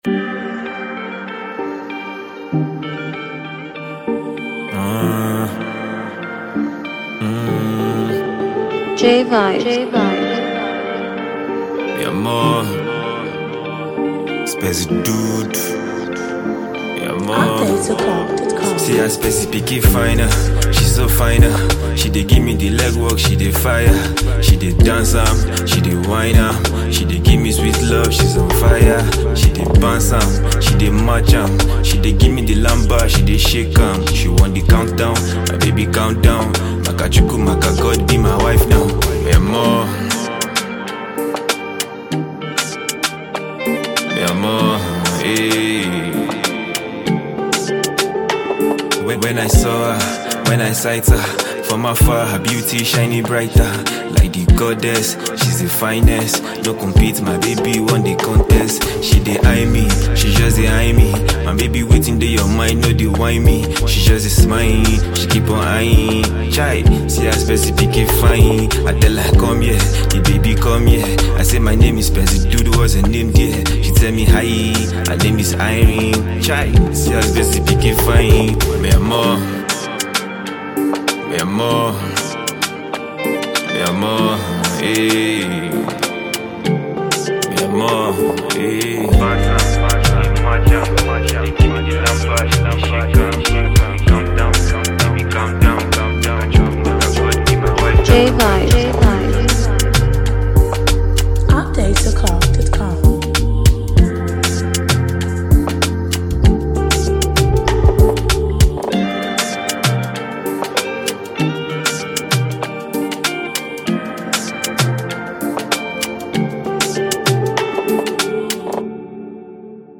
Genre: Hip hop, Rap